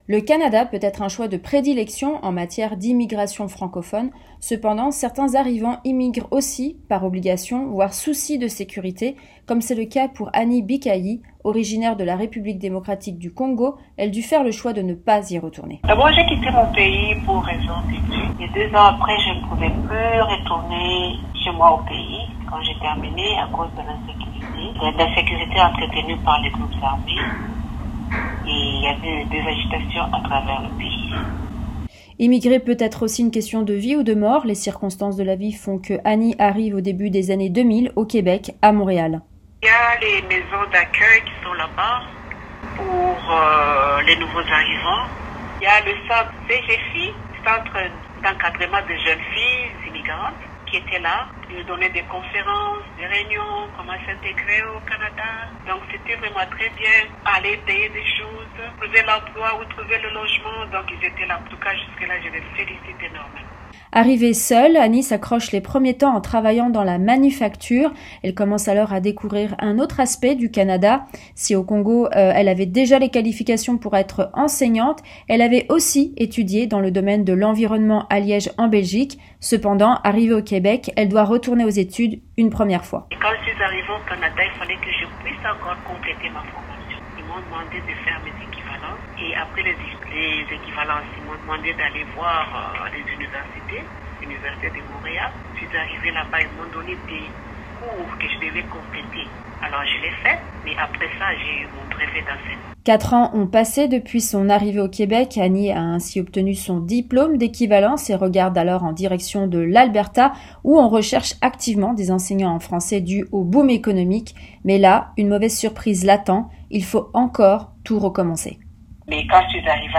Voici un long entretien